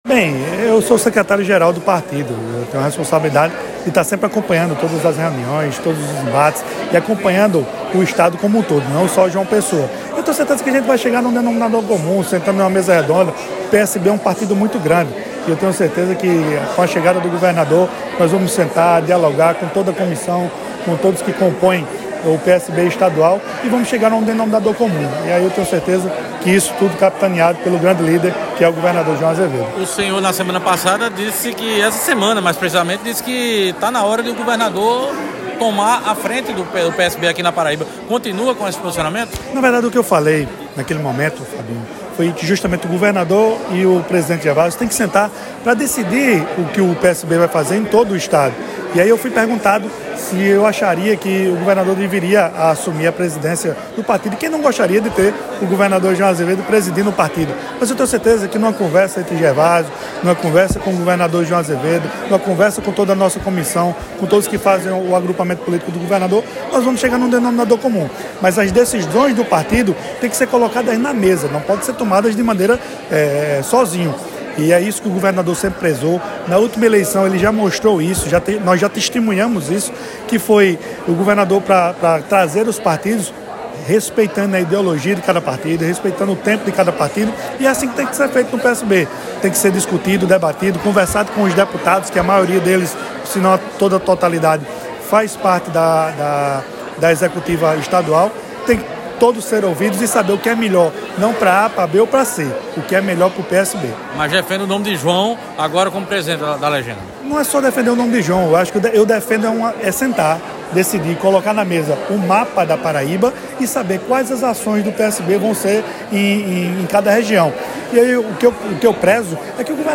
Abaixo a fala do vice-prefeito de João Pessoa, Leo Bezerra.